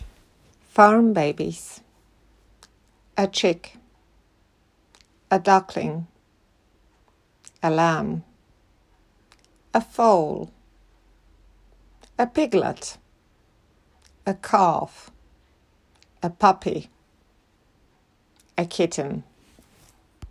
Kliknij play▶ i posłuchaj, jak wymawiam nowe wyrazy (od górnego lewego rogu do dolnego prawego).
farm-babies.m4a